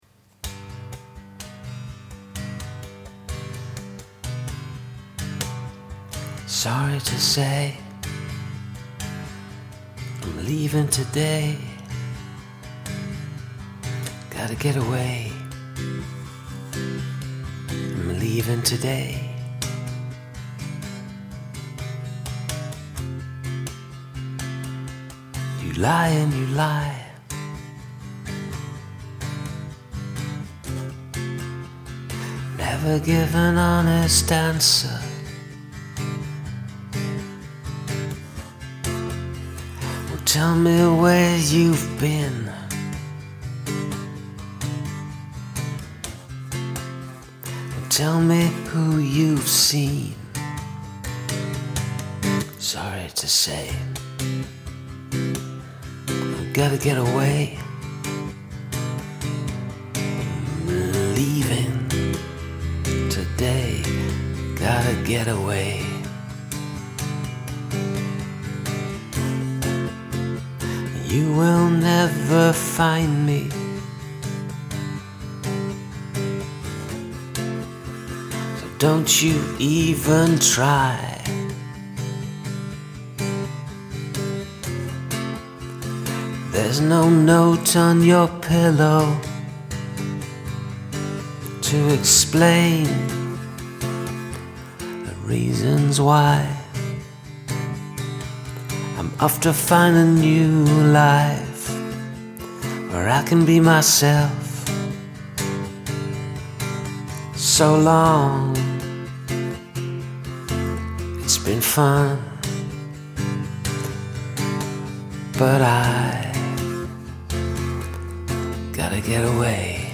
OK, couldn't really "sing" this one as it's about 0230 in the morning here and I don't want to wake the neighbours so it's a bit quiet.
There is something haunting about your way of singing every phrase.
Hey, I LIKE the whispered vocals.
the whisper-singing gives the effect that the narrator is singing this as they are quietly leaving while everyone else is asleep, before anyone wakes up or noticed. nicely done!
The quiet dynamics gives this an interesting intensity.